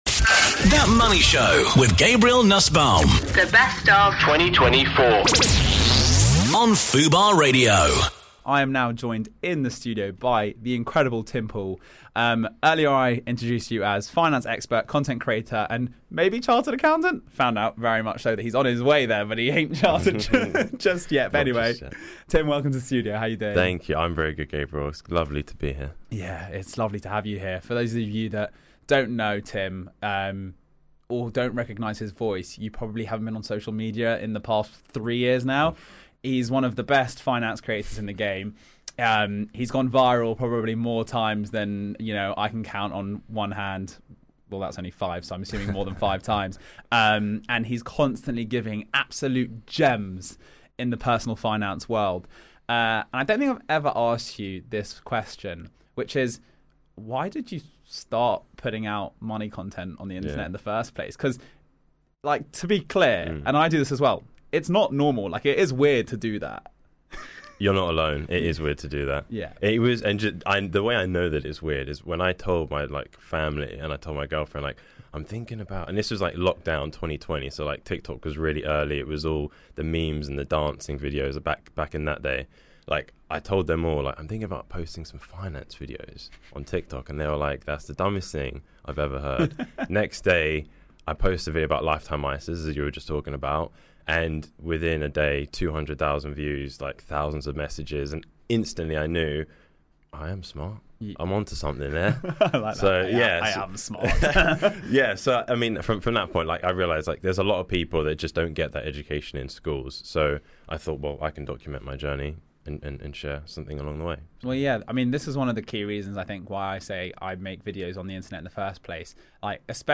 Featuring interviews